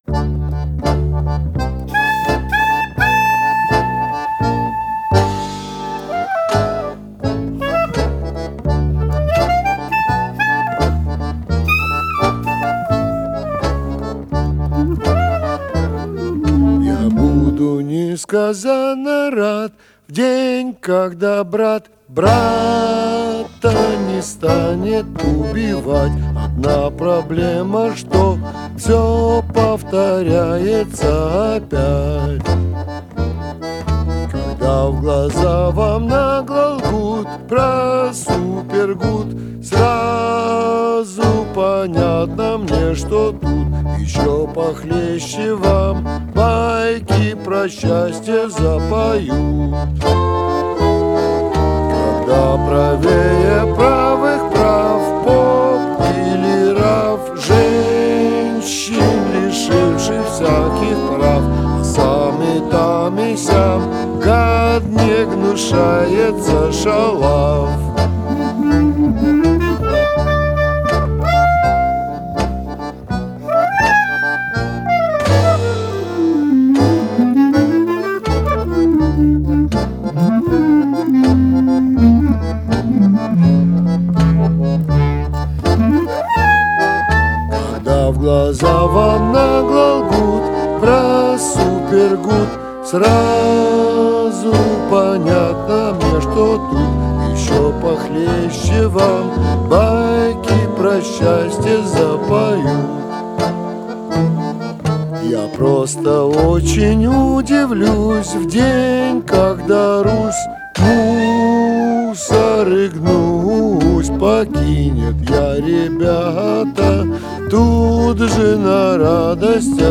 Genre: Klezmer, Balkan, Gypsy Jazz, Worldbeat